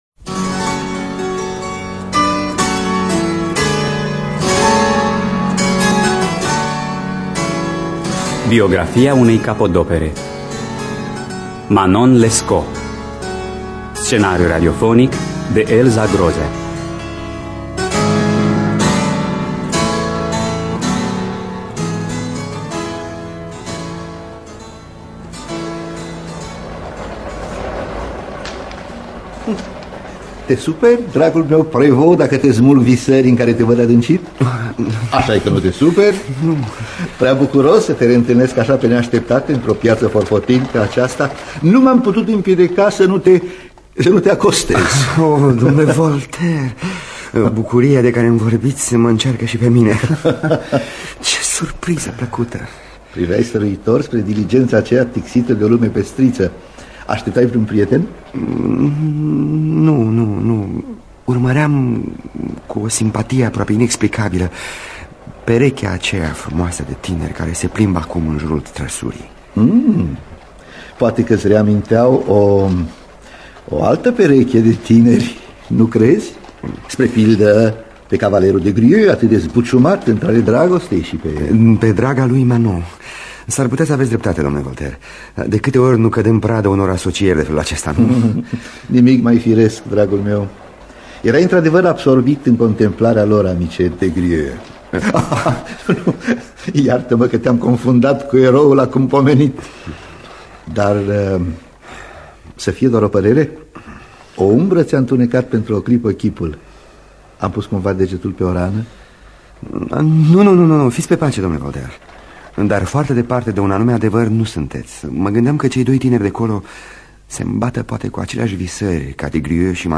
Biografii, Memorii: Manon Lescaut (1987) – Teatru Radiofonic Online